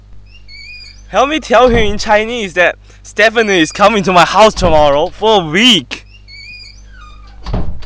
translation3_ShuttingDoor_1.wav